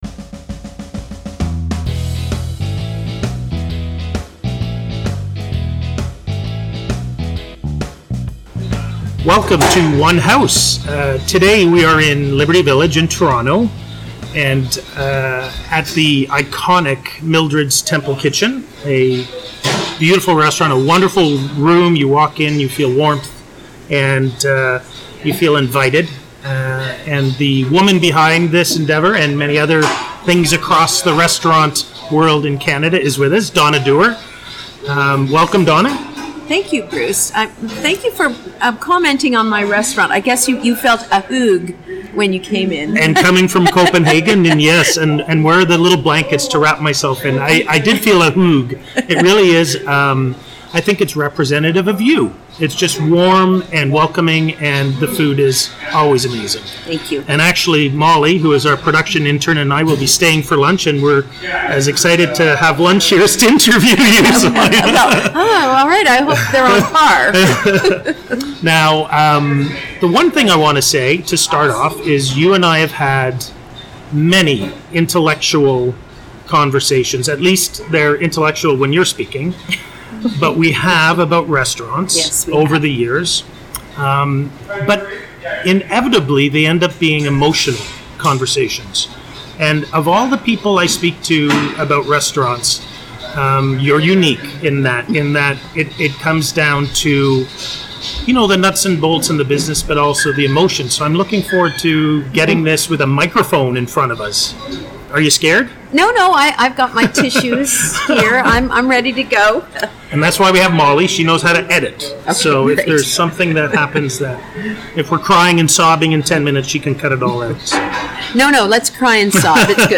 dd-interview1.mp3